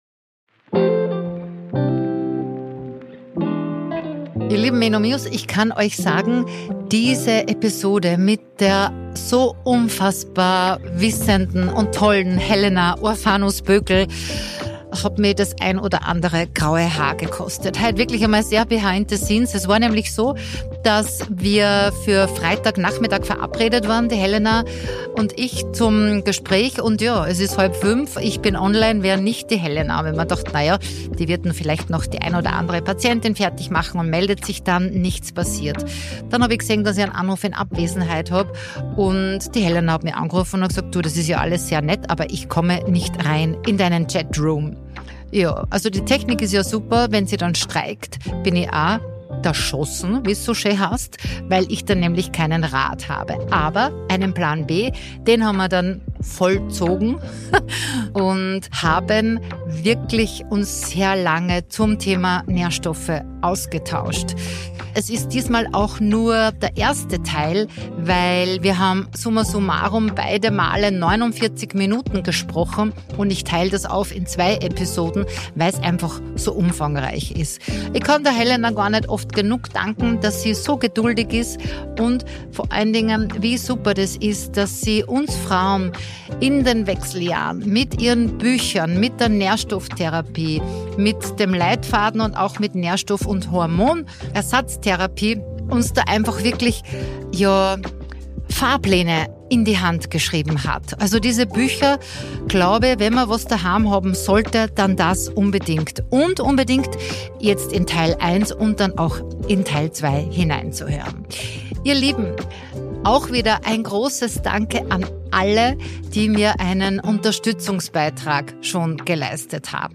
Expertinnenfolge